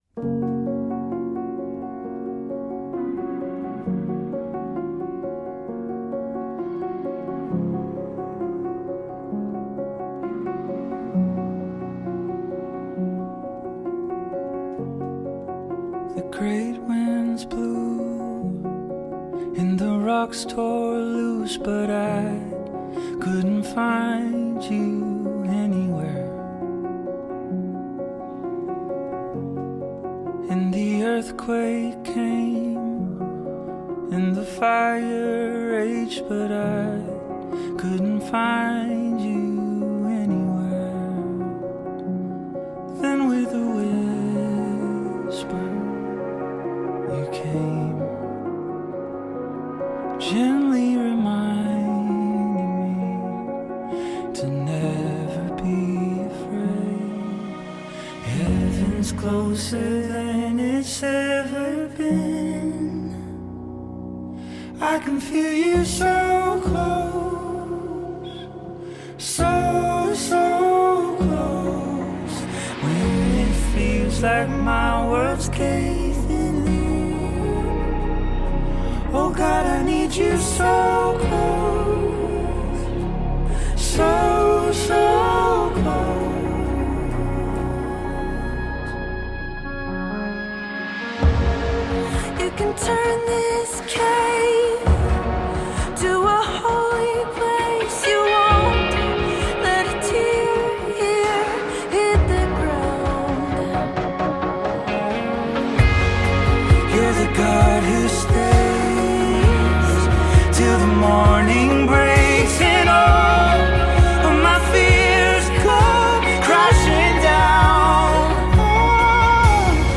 赞美诗